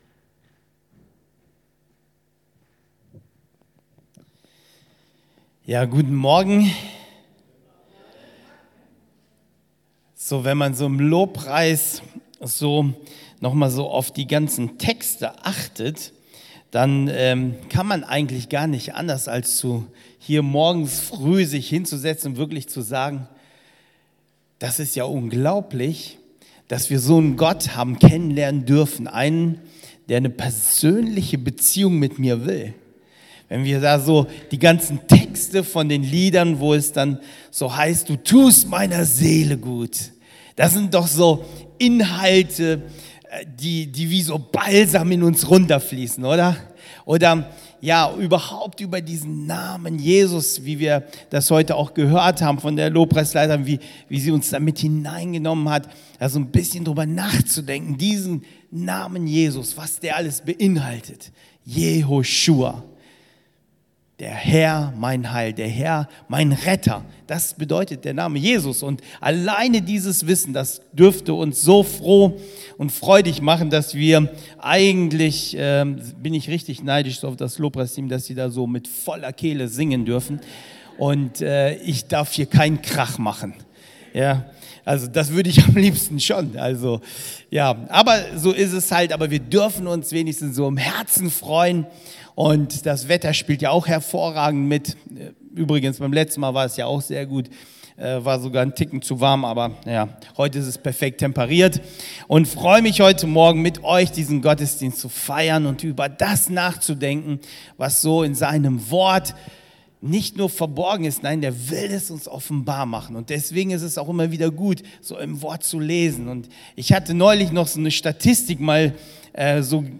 Predigt 13.09.2020